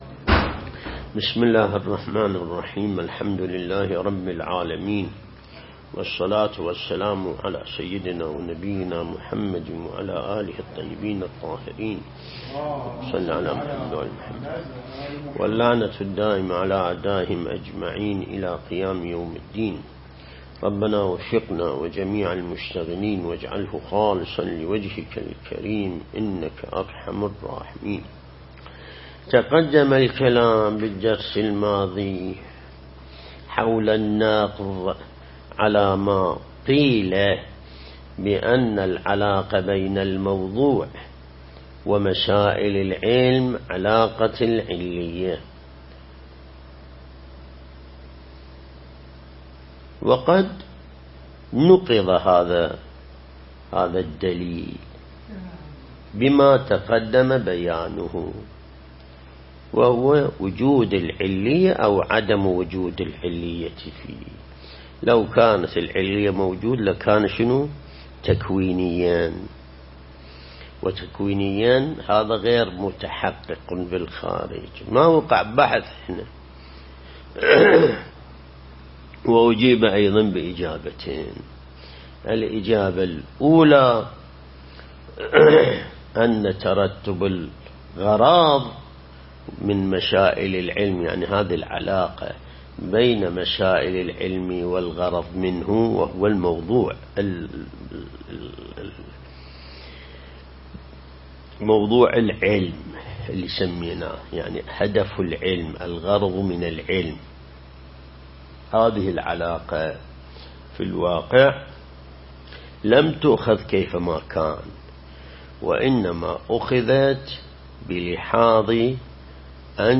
درس البحث الخارج الأصول (34)